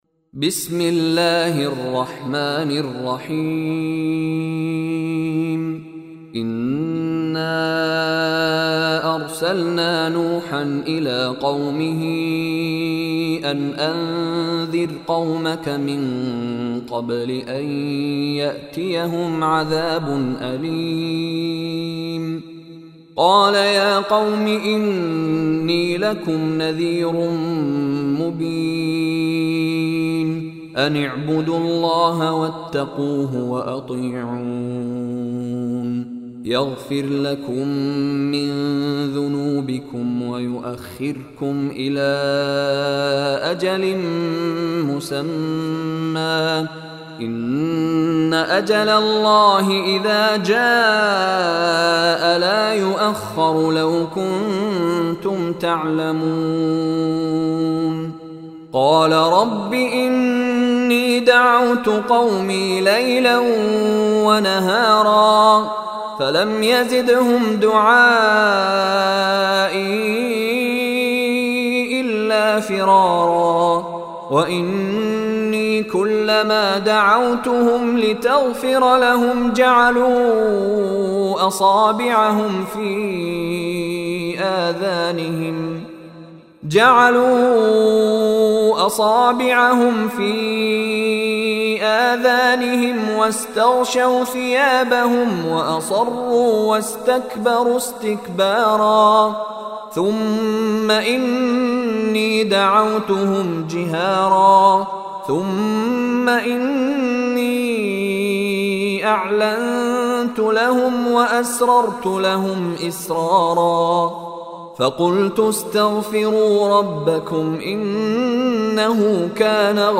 Surah Nuh Recitation by Mishary Rashid Alafasy